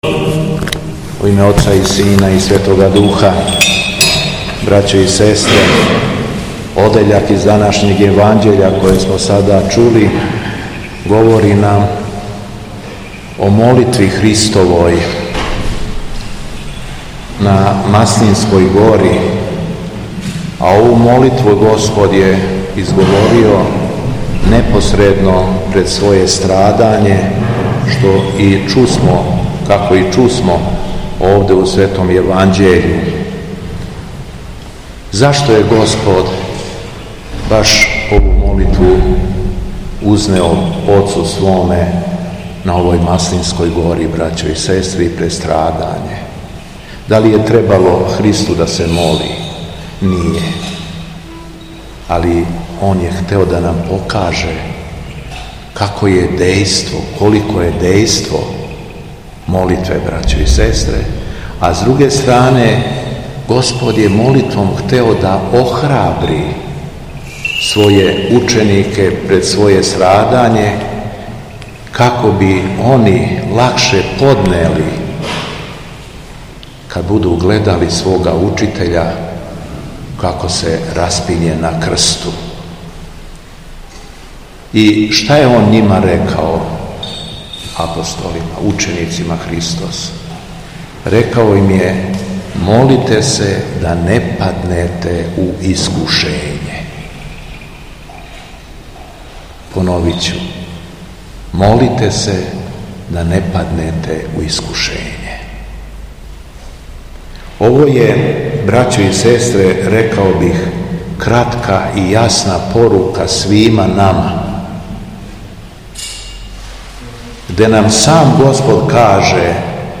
У уторак, 25. фебруара 2025. године, када наша Света Црква прославља и празнује Светог Мелетија Антиохијског, Његово Високопреосвештенство Митрополит шумадијски Г. Јован служио је Свету Архијерејску Литургију у храму Успења Пресвете Богородице у Младеновцу.
Беседа Његовог Високопреосвештенства Митрополита шумадијског г. Јована